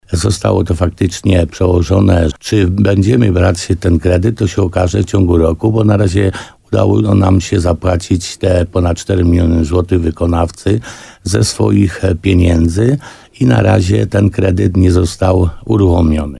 – mówił wójt gminy Piotr Stach, w programie Słowo za Słowo na antenie RDN Nowy Sącz.